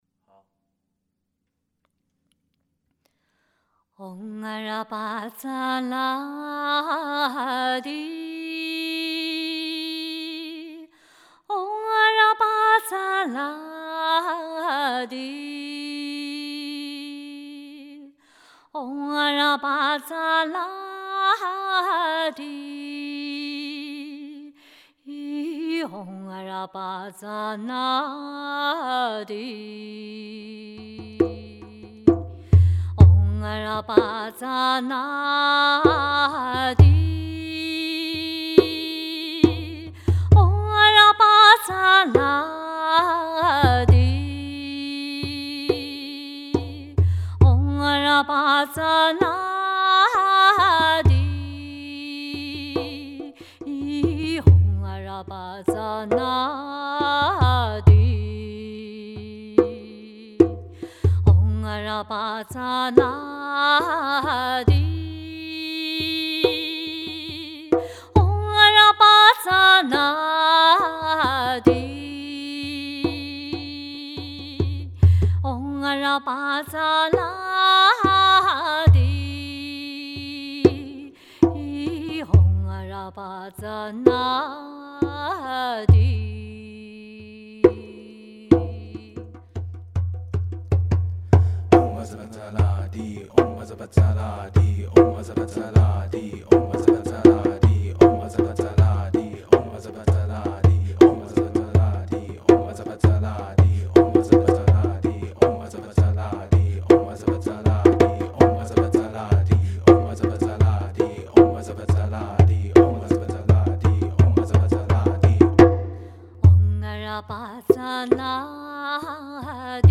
清唱